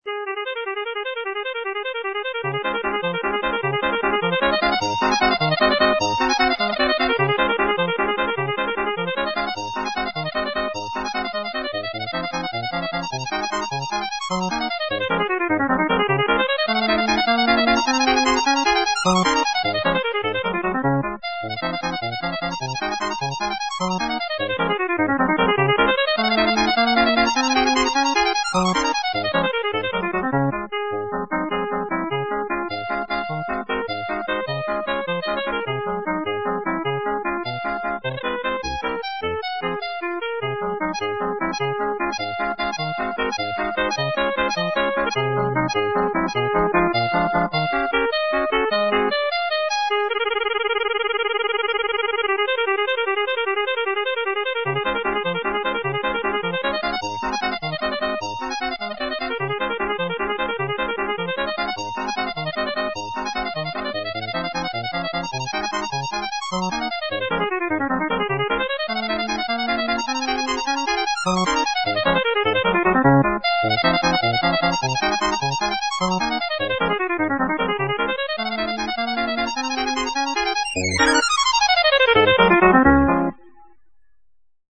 solo tema originale
Il file pcm 16 bit 44.100 kHz Mono è stato inserito in una tabella la cui lunghezza è di 84.65”.